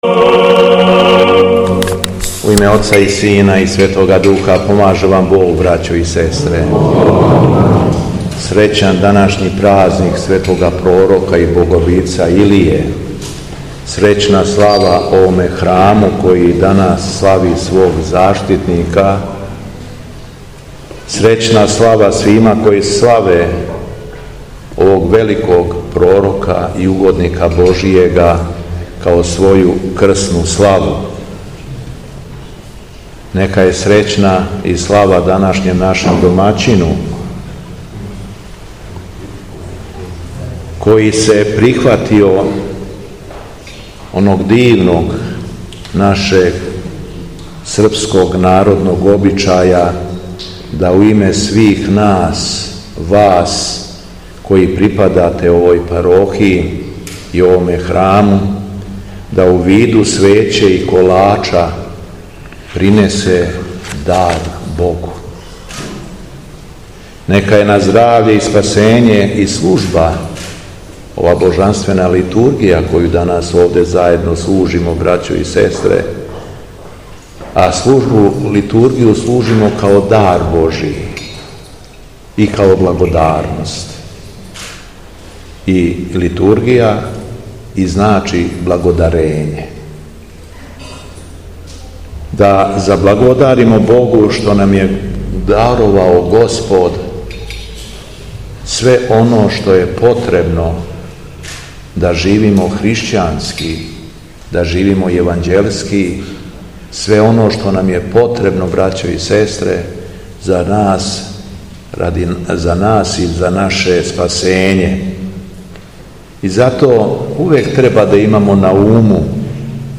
Беседа Његовог Високопреосвештенства Митрополита шумадијског г. Јована
По читању одломака из Светог Писма, Митрополит је окупљеним верницима честитао храмовну славу и поучио их беседом: